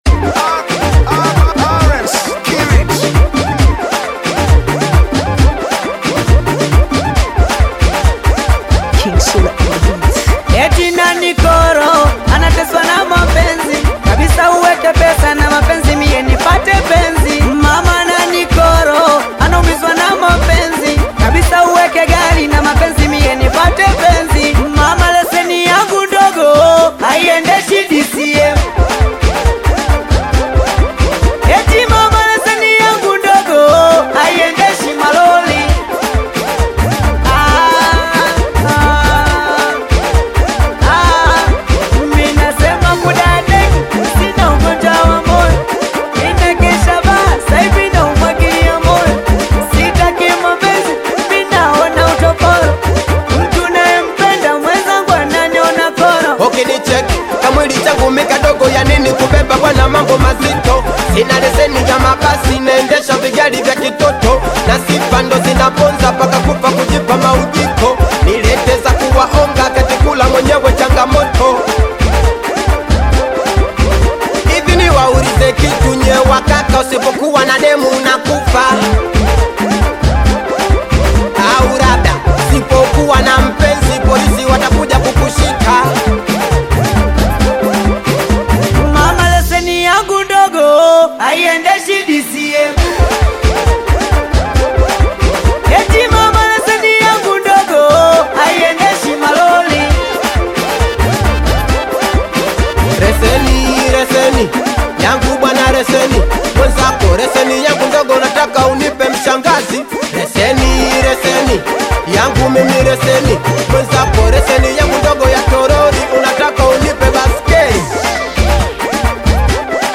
Tanzanian bongo flava singeli
African Music